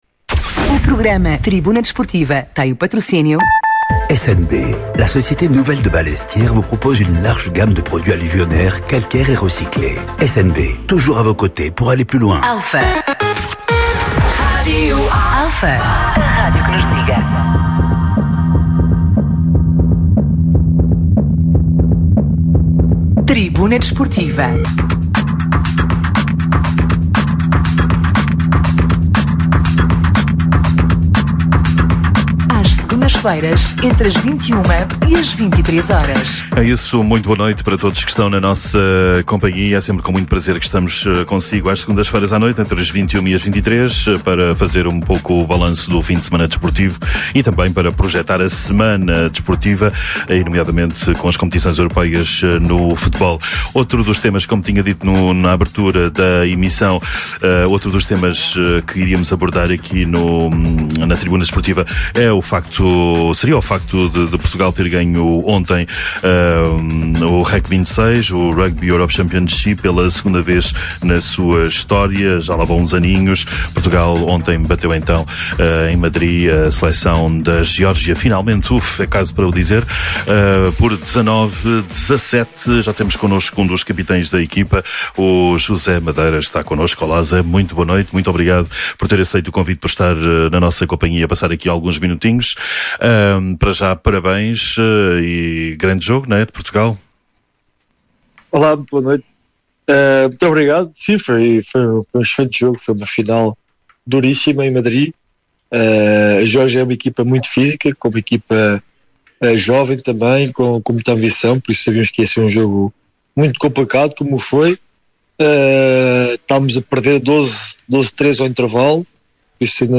Atualidade Desportiva, Entrevistas, Comentários, Crónicas e Reportagens.
Tribuna Desportiva é um programa desportivo da Rádio Alfa às Segundas-feiras, entre as 21h e as 23h.